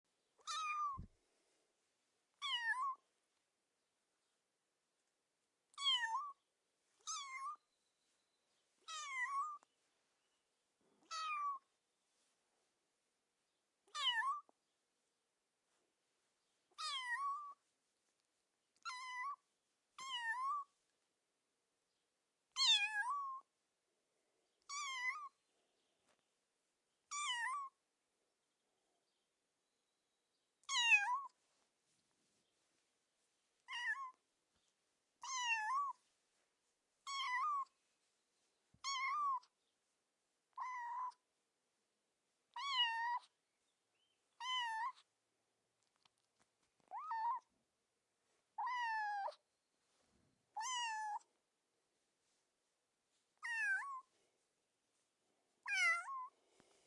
Download Kitten sound effect for free.
Kitten